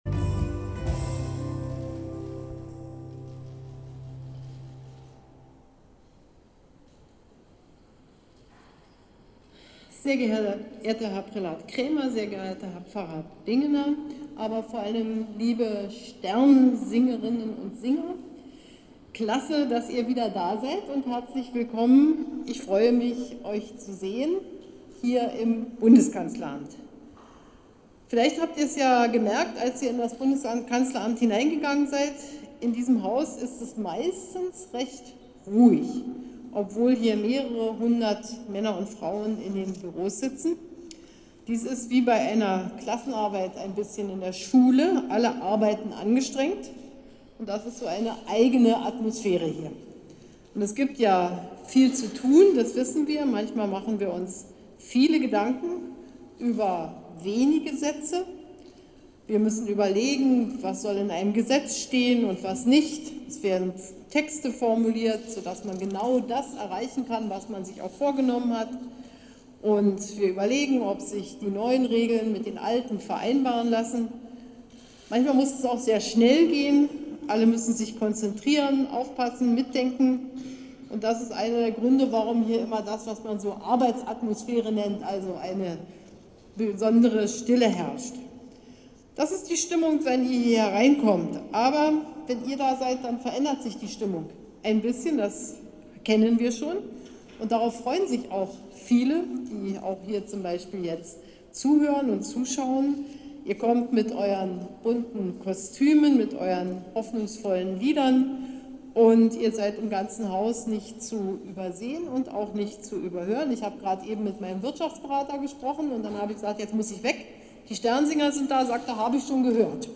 Rede_Kanzlerin.mp3